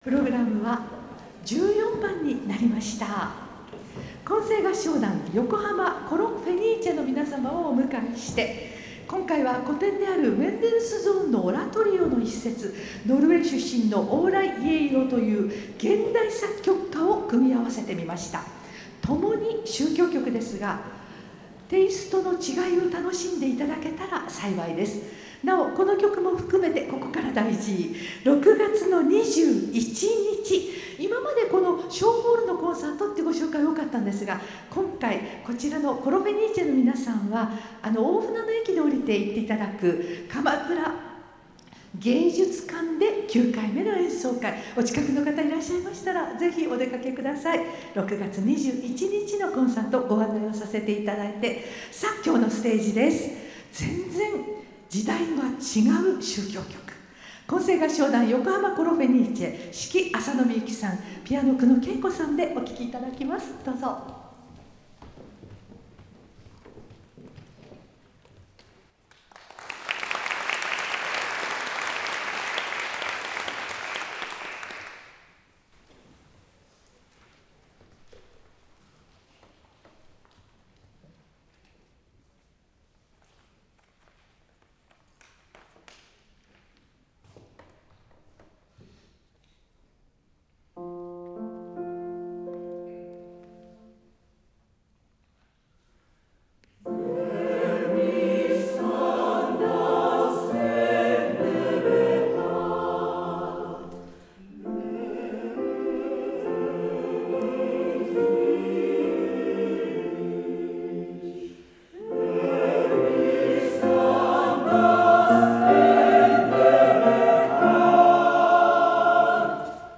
fenice_symbol 混声合唱団
○場所：ヨコハマみなとみらいホール／小ホール
ピアノ
[実況録音(.mp3)]
丁寧な歌いぶりで、基本が出来ているなあ、と感じ
アンサンブルが素晴らしいです！
品格ある響き、緻密な音楽